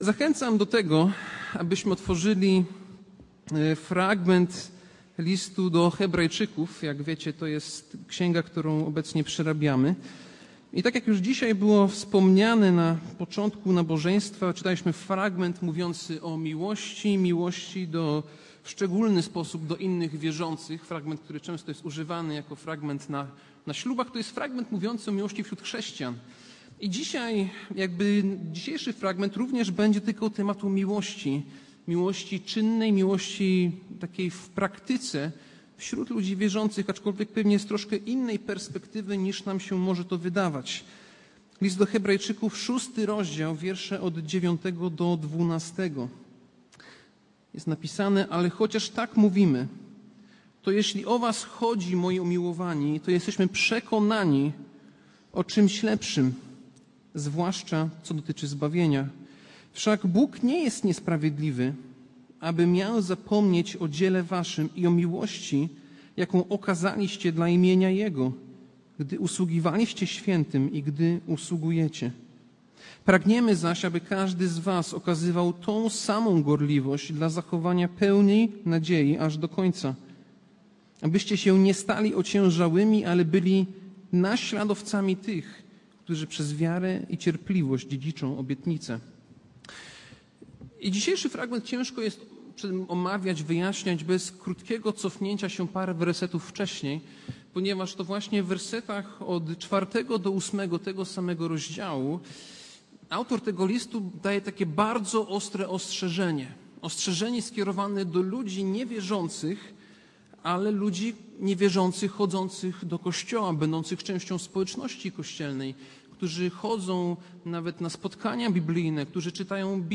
Passage: List do Hebrajczyków 6, 9-12 Kazanie